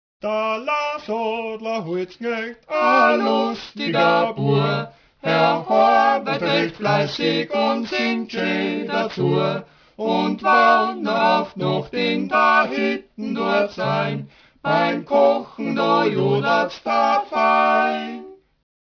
(Texte und Gesangsproben).
Der Lahnsattler Holzknecht (Zeller Dreigesang), aus: Über das Jodeln